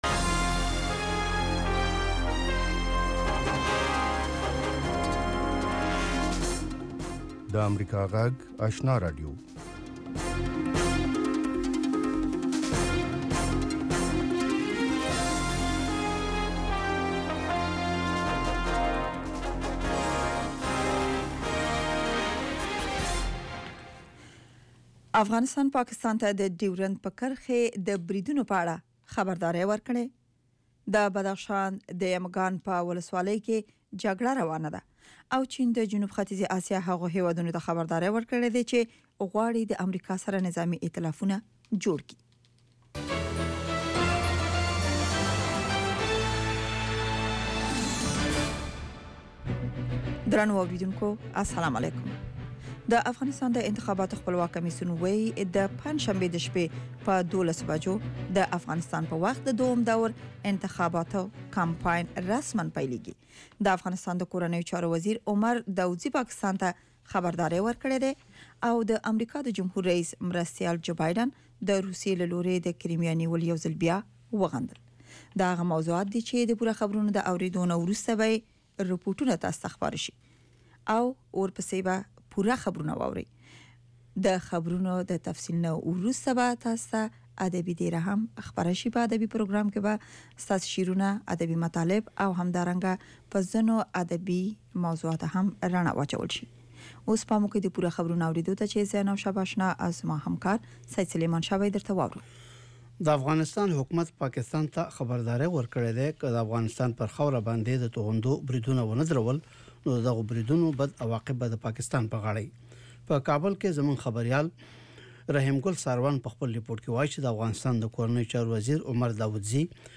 یو ساعته خپرونه: تازه خبرونه، د ځوانانو، میرمنو، روغتیا، ستاسو غږ، ساینس او ټیکنالوژي، سندرو او ادب په هکله اونیز پروگرامونه.